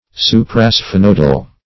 suprasphenoidal - definition of suprasphenoidal - synonyms, pronunciation, spelling from Free Dictionary
Search Result for " suprasphenoidal" : The Collaborative International Dictionary of English v.0.48: Suprasphenoidal \Su`pra*sphe*noid"al\, a. (Anat.) Situated above the sphenoidal bone; as, the suprasphenoidal appendage, or pituitary body.